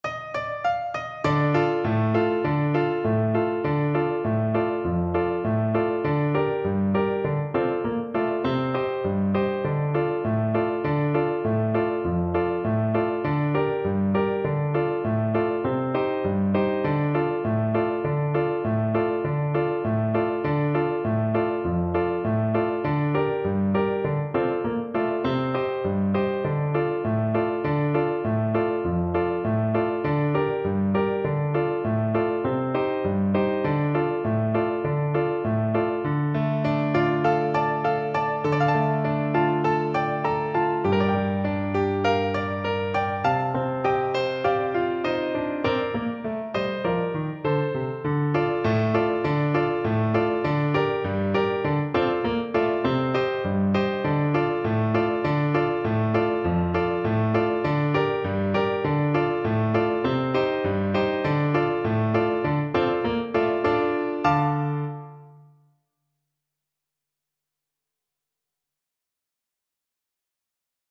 Flute
Traditional Music of unknown author.
Bb major (Sounding Pitch) (View more Bb major Music for Flute )
Steadily =100
2/4 (View more 2/4 Music)